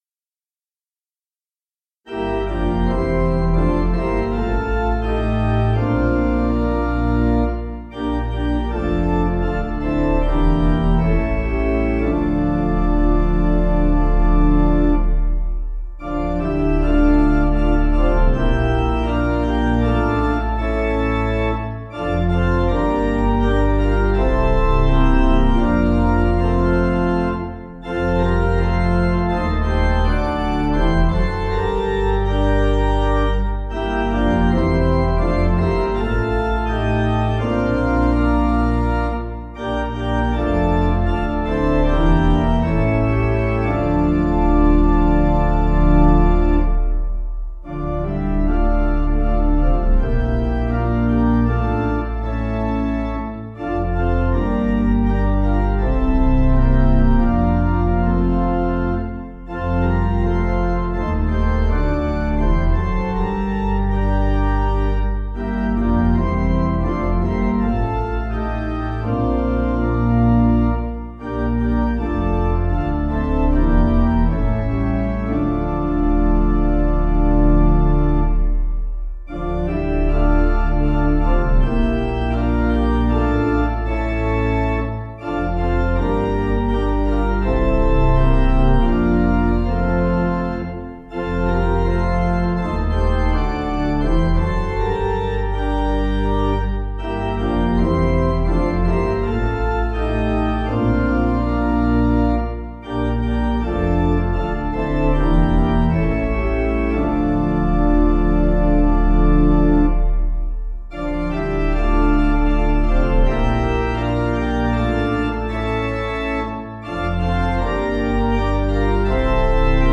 Finnish Melody
Organ
(CM)   4/Dm 482.5kb